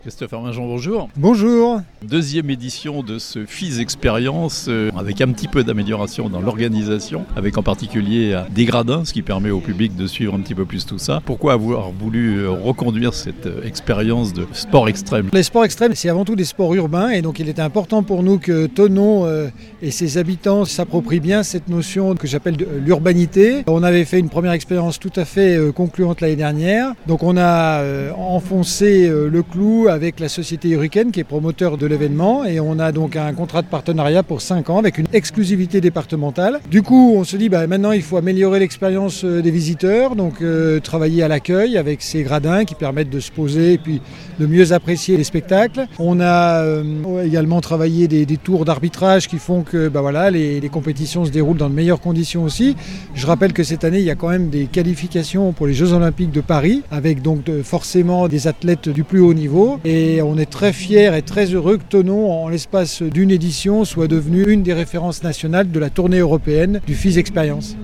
2ème édition du FISE XPERIENCE les 12, 13 et 14 août à Thonon (interview)
Le lancement de cett étape thononaise du FISE XPERIENCE a été assuré par le Maire de Thonon, Christophe Arminjon.